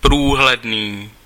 Ääntäminen
France: IPA: /tʁɑ̃s.pa.ʁɑ̃/